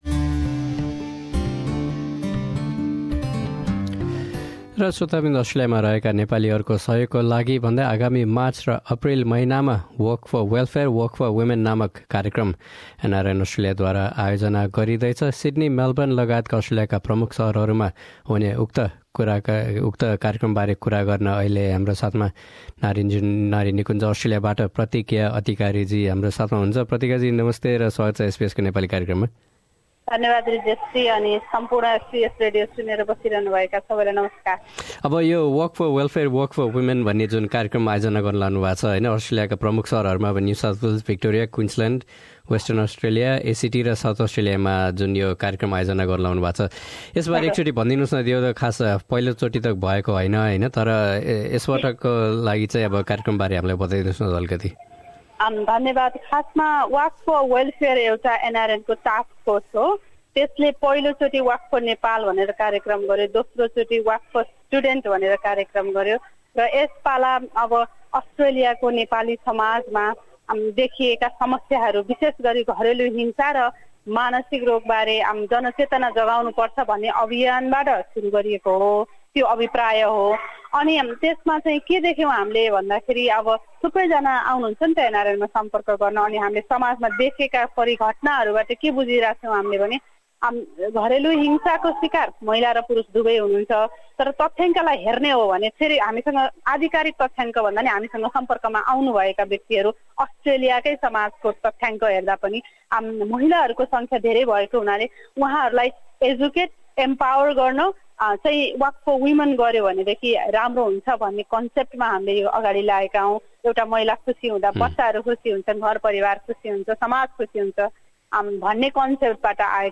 एसबीएस नेपालीसँग गरेको कुराकानी।